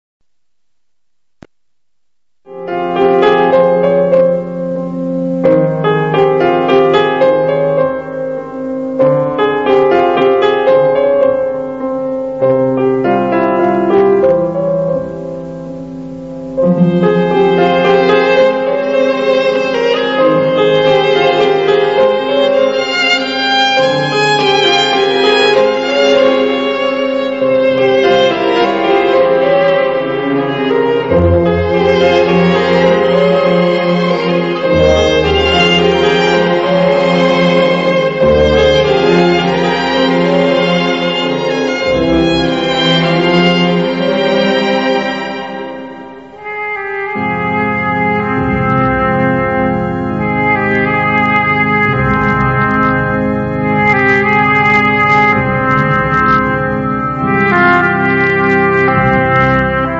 reposado minimalismo melódico